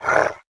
damage_2.wav